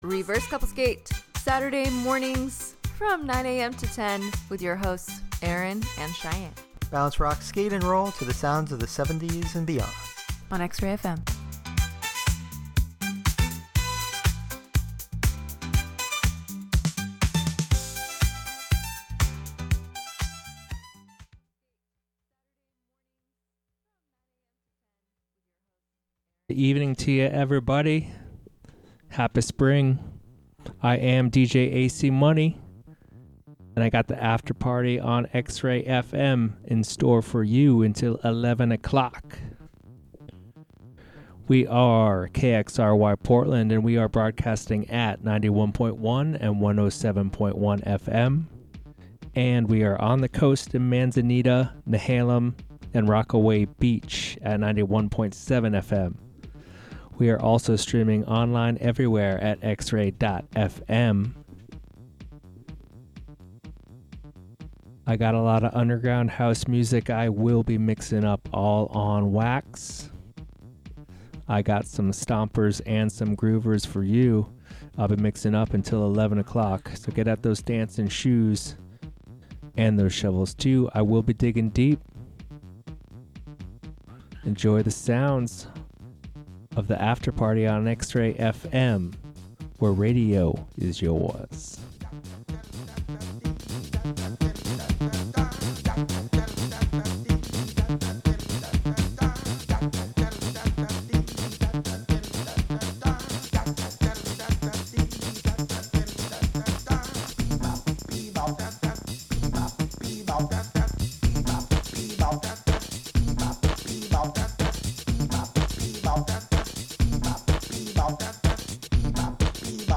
Get down to some stops and grooves